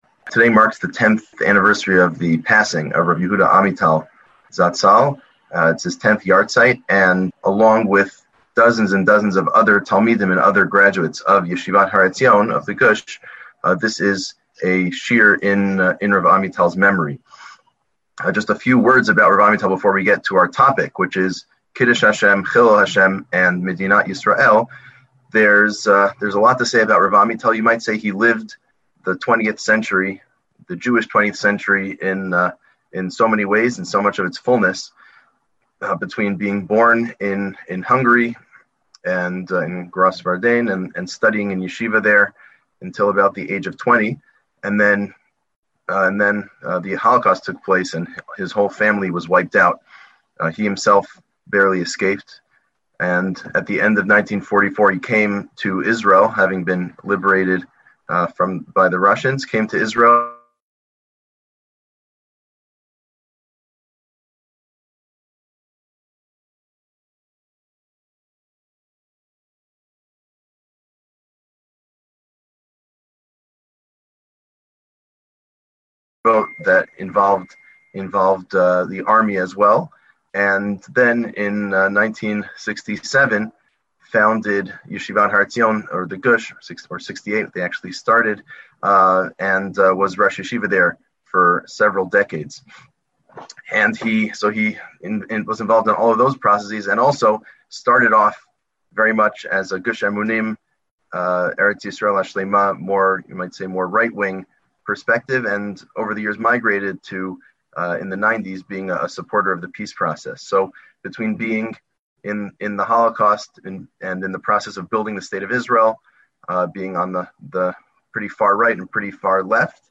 A shiur